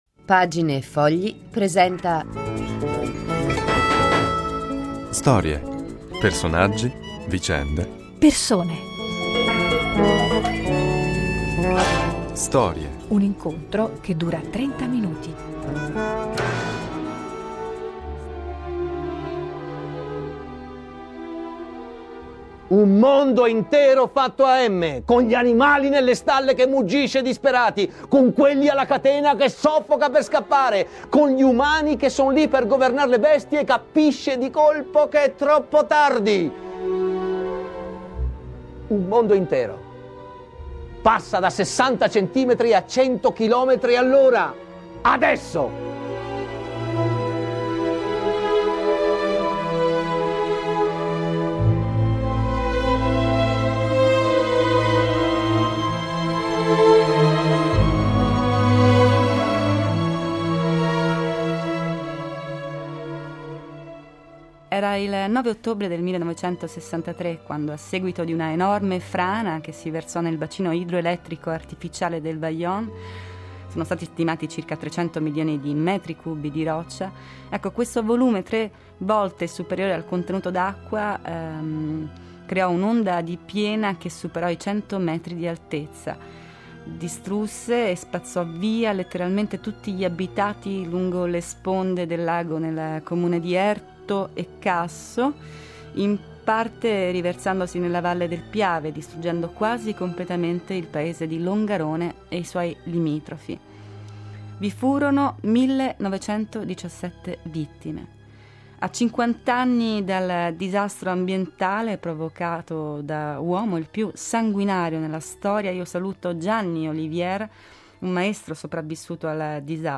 Questa è la storia del Vajont un olocausto che provocò 1917 vittime di cui oltre 800 solo bambini. A 50 anni di distanza un sopravvissuto